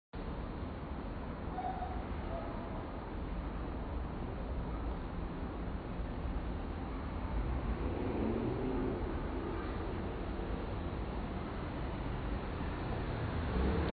中正區臨沂街巷弄 | 尋找55分貝靜土
均能音量: 50 dBA
最大音量: 65.6 dBA 地點類型: 巷弄 寧靜程度: 4分 (1分 – 非常不寧靜，5分 – 非常寧靜)
說明描述: 這個巷弄給你感覺很有氣質，而且聲音讓人覺得很平靜寧靜的氛圍 聲音類型: 戶外